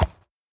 Minecraft Version Minecraft Version snapshot Latest Release | Latest Snapshot snapshot / assets / minecraft / sounds / mob / horse / soft2.ogg Compare With Compare With Latest Release | Latest Snapshot